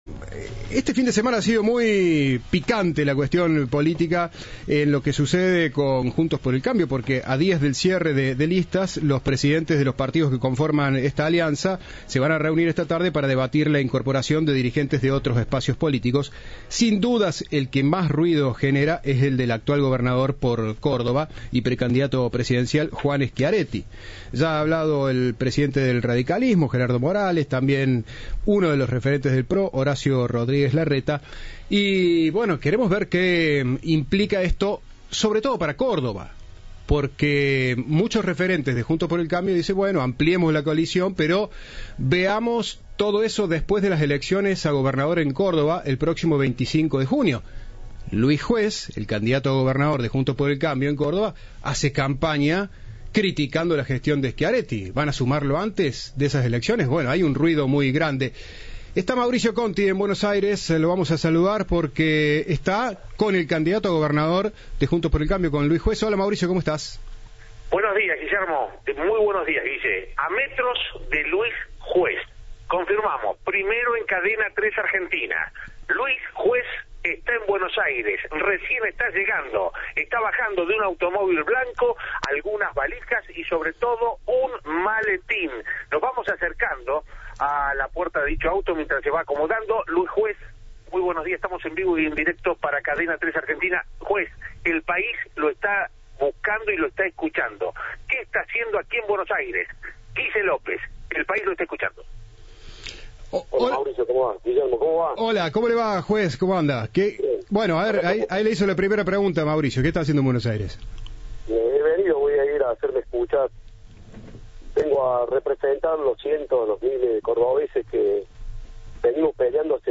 El senador nacional y candidato a gobernador de Córdoba dialogó en exclusiva con Cadena 3 y rechazó la incorporación de Schiaretti a Juntos por el Cambio: Aquí no se rinde nadie.